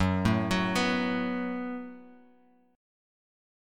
F#mbb5 chord